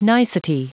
Prononciation du mot nicety en anglais (fichier audio)
Prononciation du mot : nicety